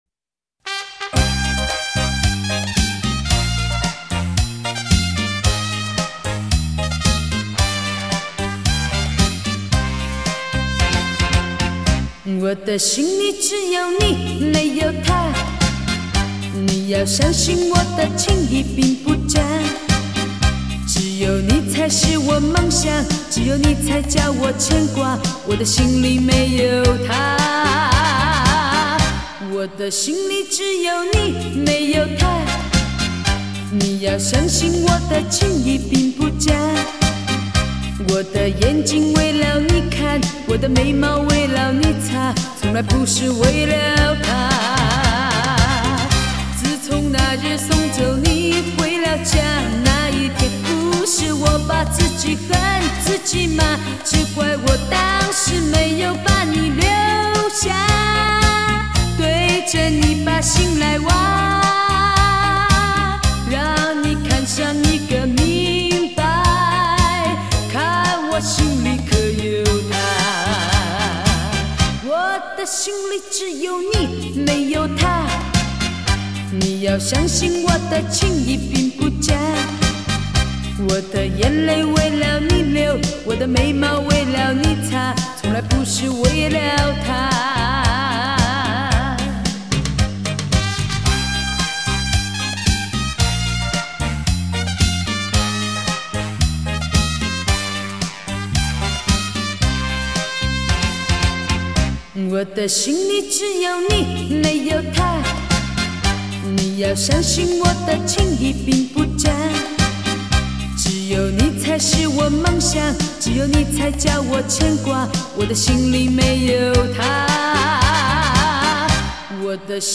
舞厅规格
恰恰歌唱版